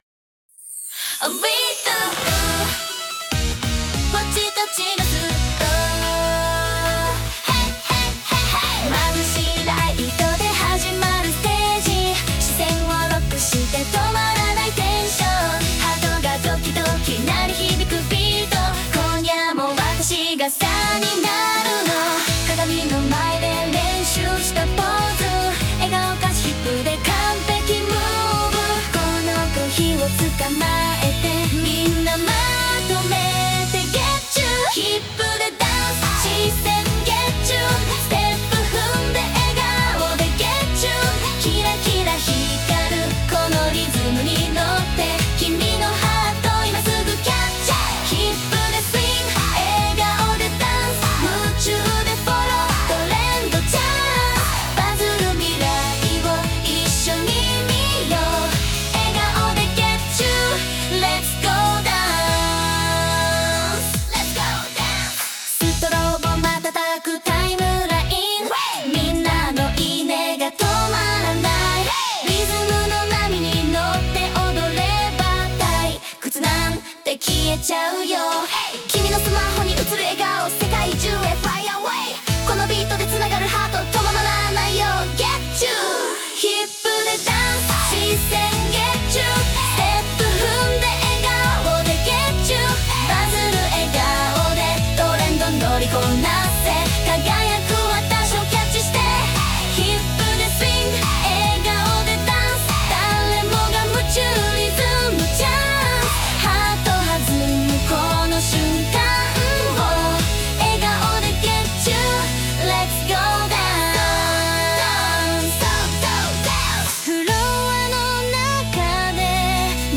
SNSで話題になること間違いなしのキュートなポップナンバー！